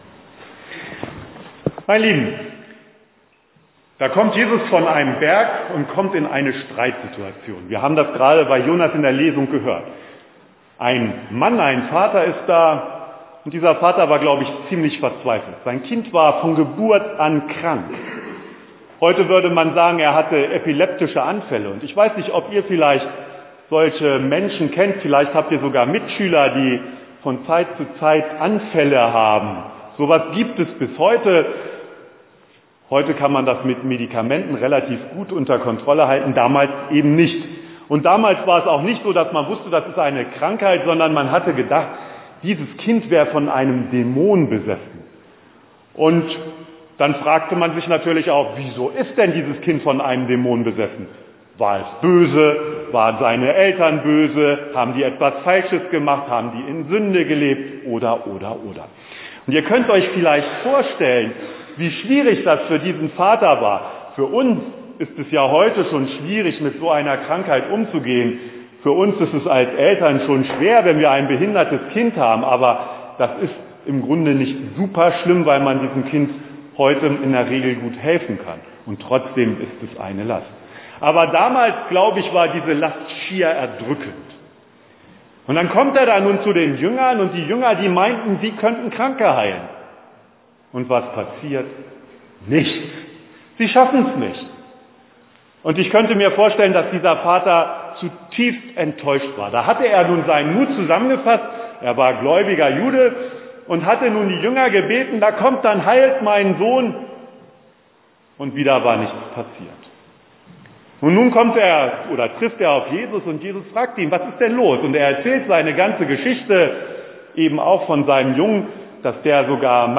Gestern fand der erste Good Time Gottesdienst 2020 in der Gielder Kirche statt.